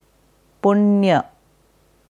Sanskrit Punya korrekte Aussprache anhören
Hier kannst du dir anhören, wie ein Sanskrit Experte, eine Sanskritkennerin Punya ausspricht.